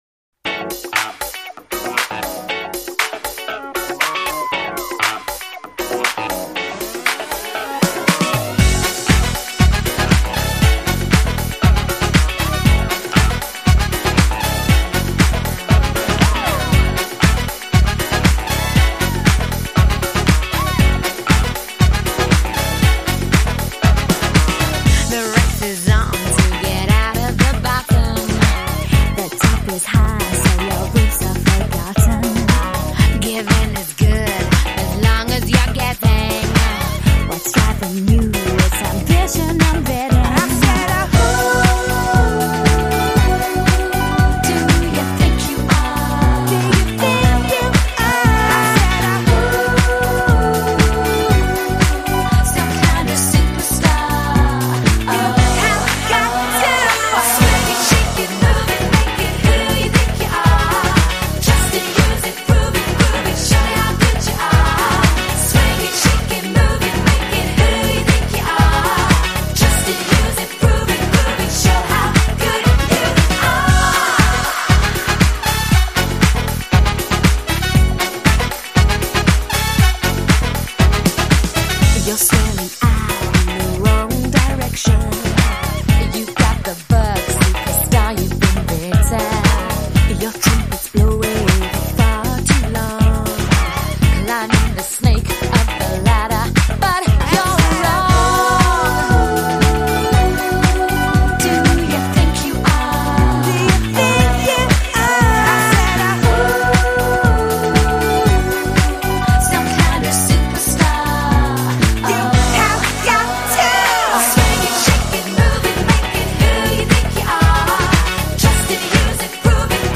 и появился диско бэк-трек